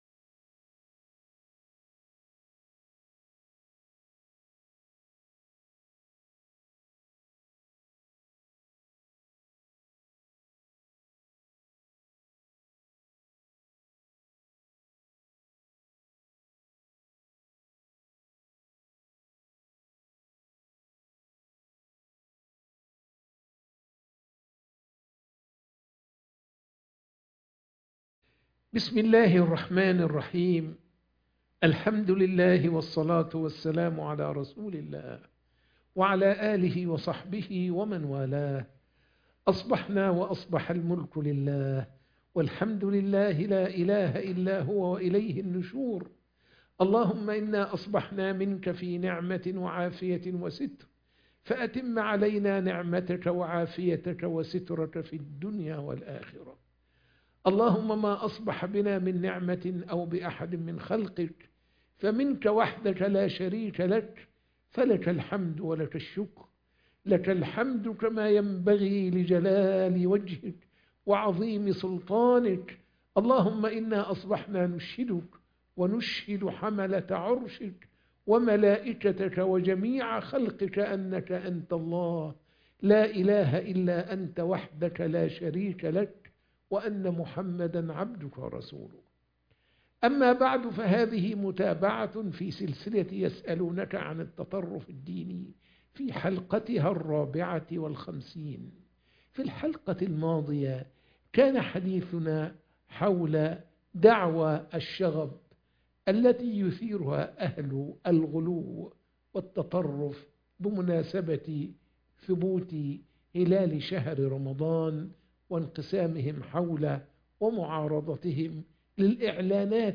درس الفجر - يسألونك عن التطرف الديني